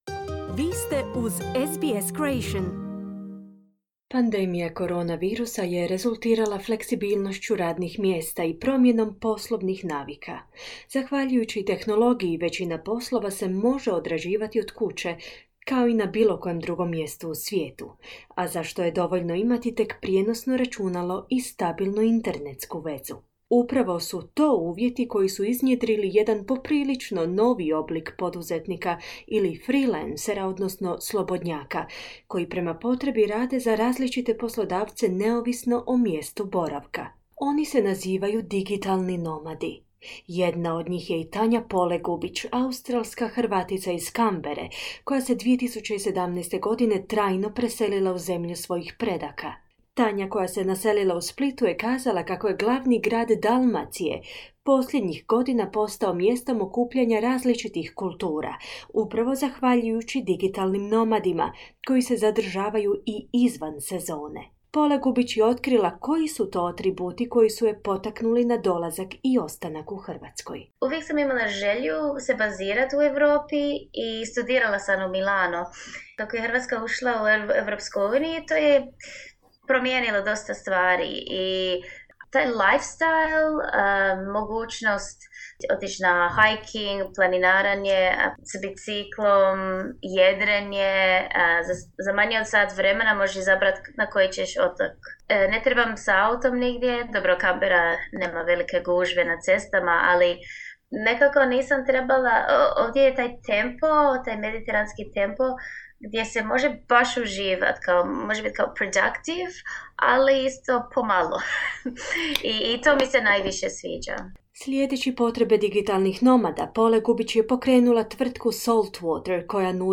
u razgovoru s digitalnim nomadima